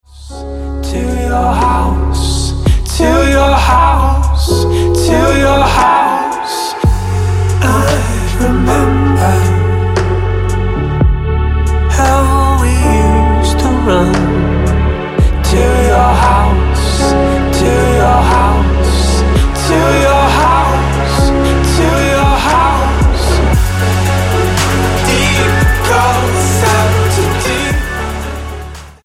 Dance/Electronic Single
Style: Dance/Electronic